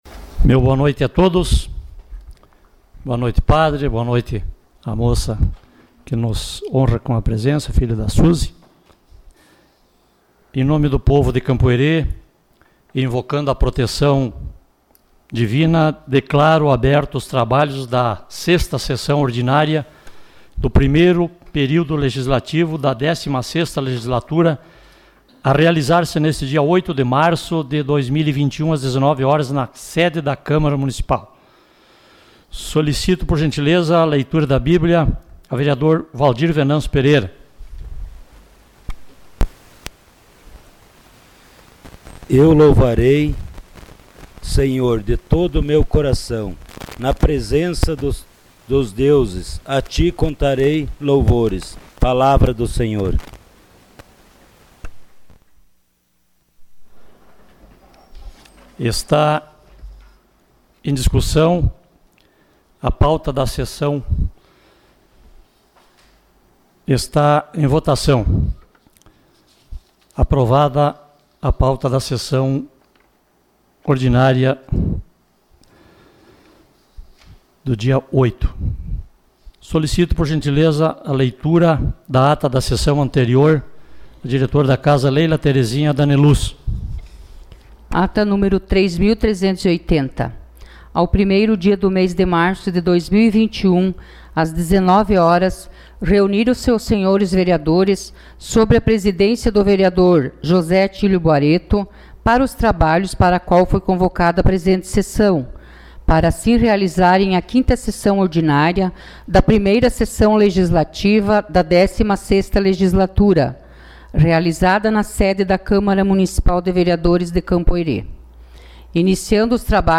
SESSÃO ORDINÁRIA DIA 08 DE MARÇO DE 2021